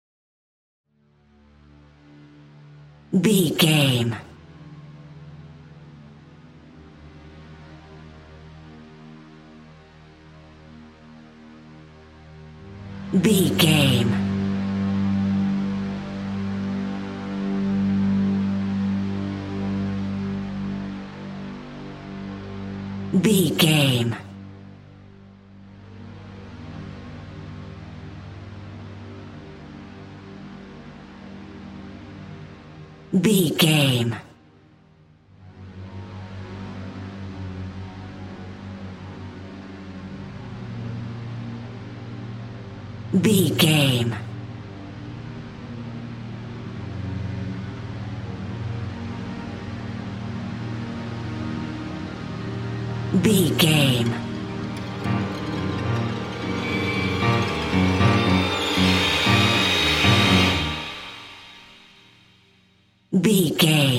Dramatic Killer Music.
Aeolian/Minor
ominous
haunting
eerie
strings
cymbals
gongs
viola
french horn trumpet
taiko drums
timpani